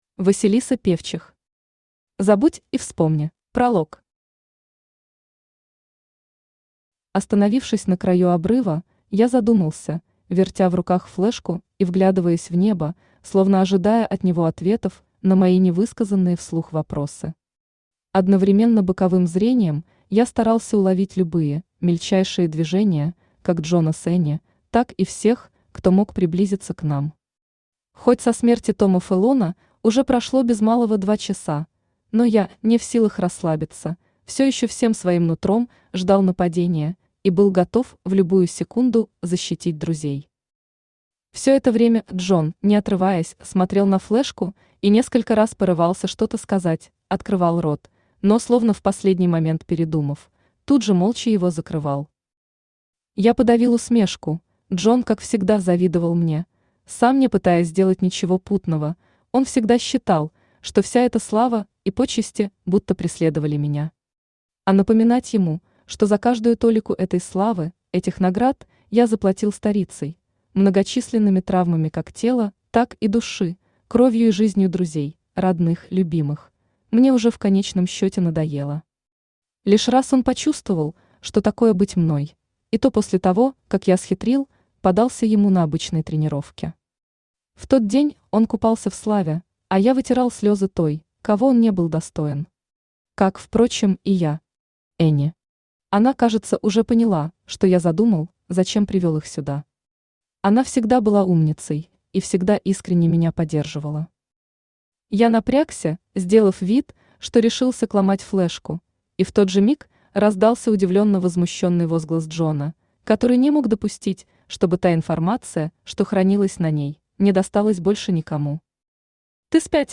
Аудиокнига Забудь и вспомни | Библиотека аудиокниг
Aудиокнига Забудь и вспомни Автор Василиса Певчих Читает аудиокнигу Авточтец ЛитРес.